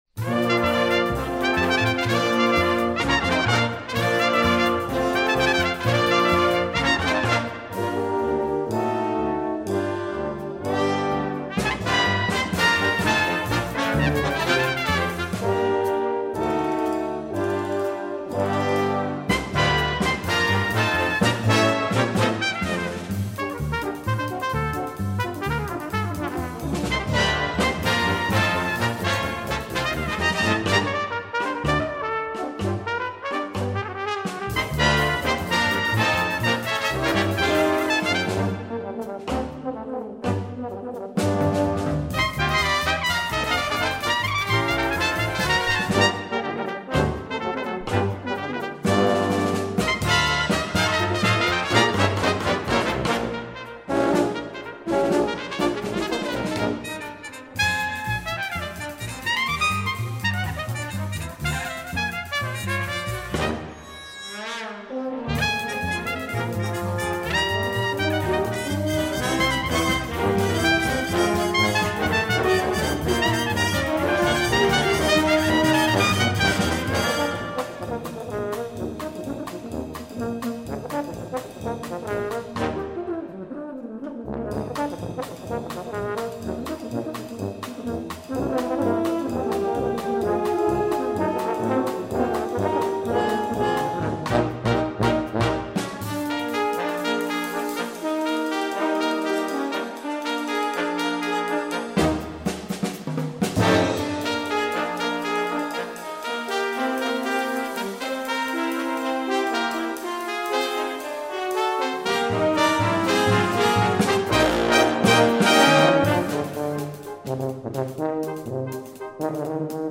And a few clips from our recording session on 4/23/07